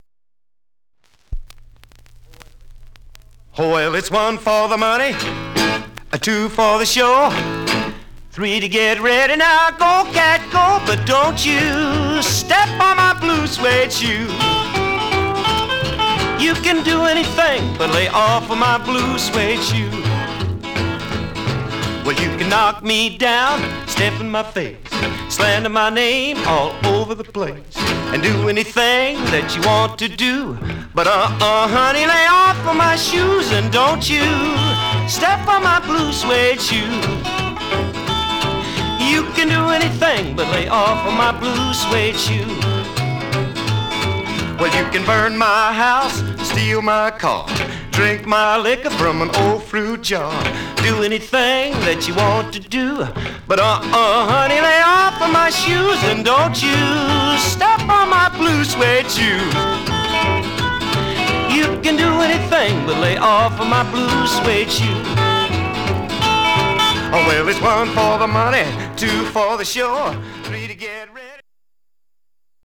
Condition Surface noise/wear Stereo/mono Mono
Rockabilly